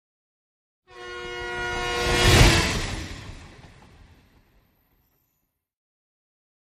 Train Whistle By European 2 - L to R